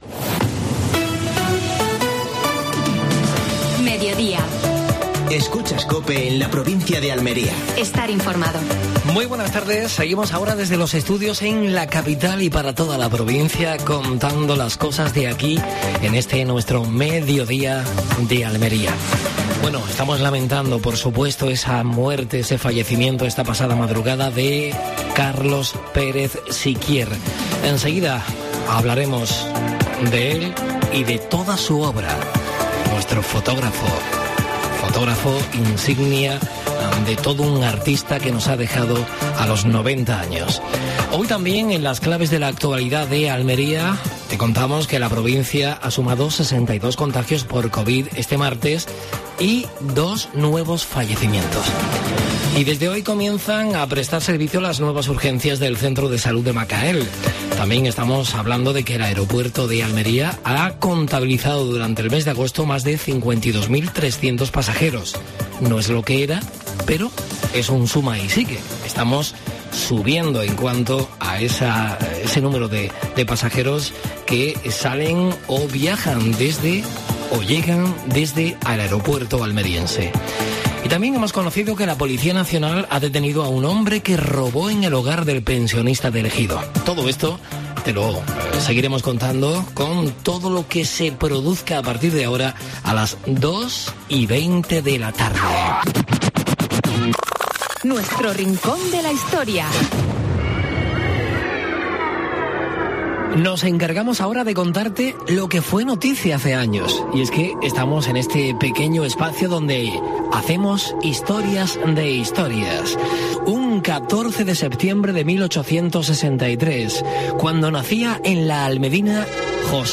AUDIO: Actualidad en Almería. José Antonio Maldonado nos trae la previsión meteorológica para toda la semana. Última hora deportiva.